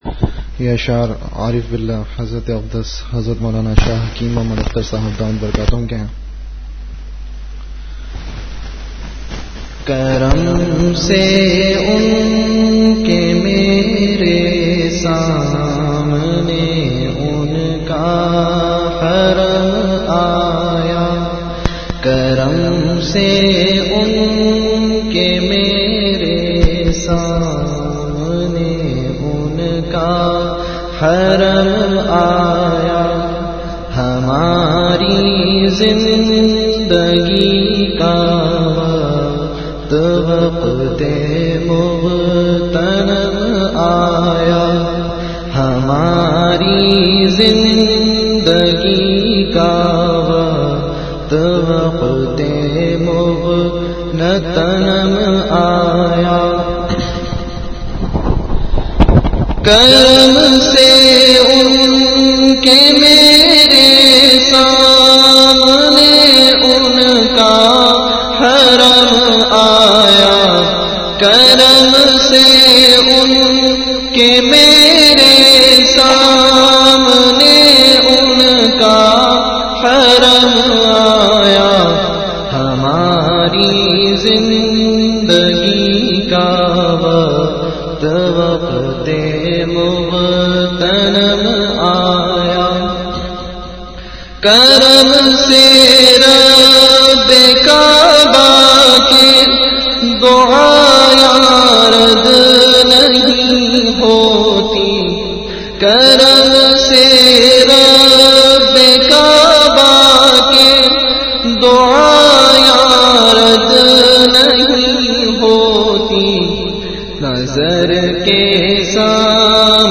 Majlis-e-Zikr
After Isha Prayer